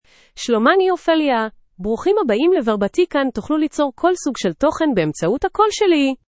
OpheliaFemale Hebrew AI voice
Ophelia is a female AI voice for Hebrew (Israel).
Voice sample
Listen to Ophelia's female Hebrew voice.
Female
Ophelia delivers clear pronunciation with authentic Israel Hebrew intonation, making your content sound professionally produced.